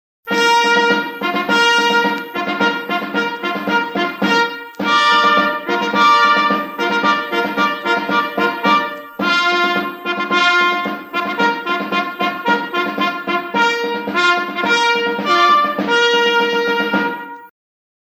Здесь будет погромчее